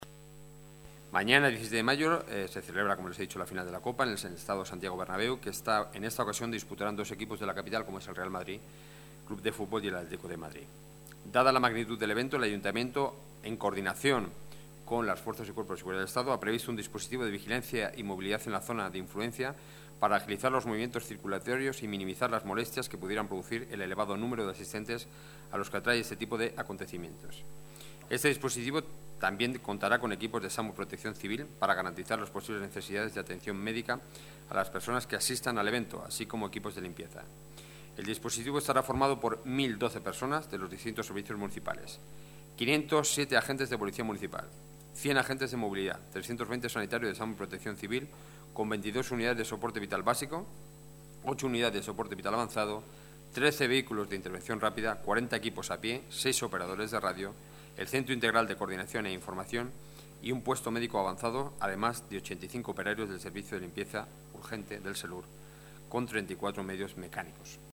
Nueva ventana:Declaraciones de Enrique Núñez, portavoz del Gobierno municipal